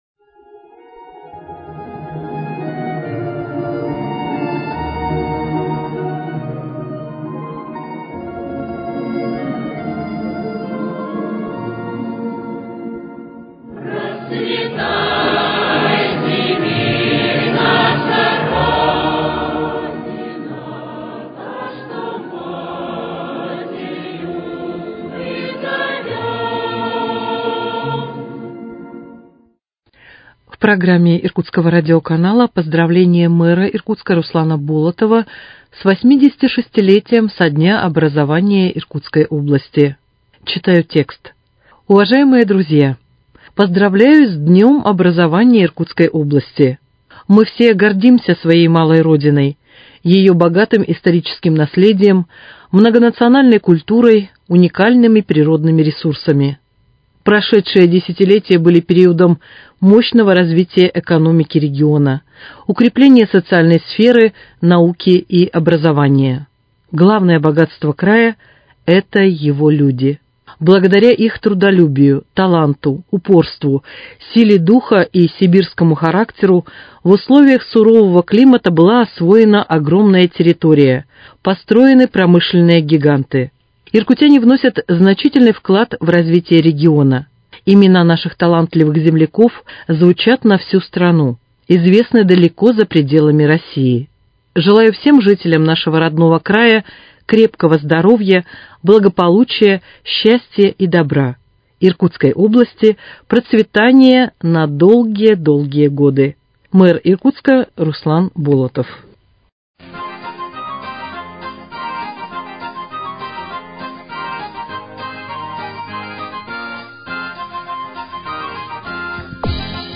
Поздравление мэра Иркутска Руслана Болотова с Днём образования Иркутской области